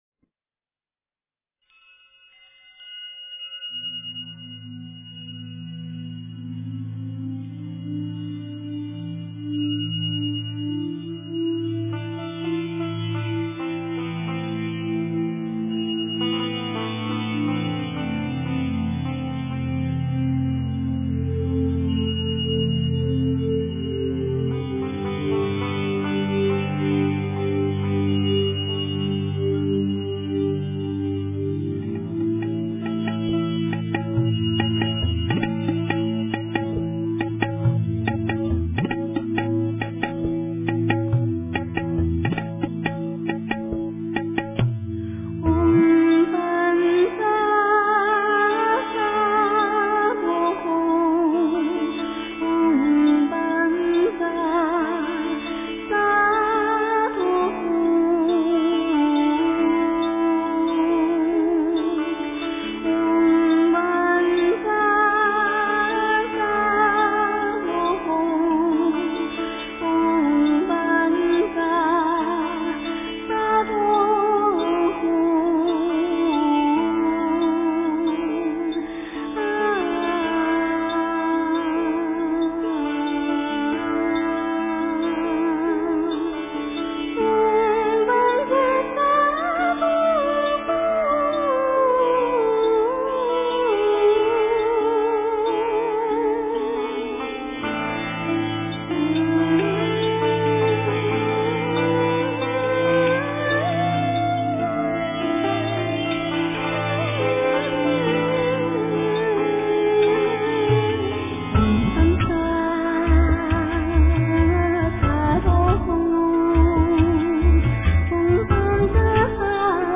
标签: 佛音真言佛教音乐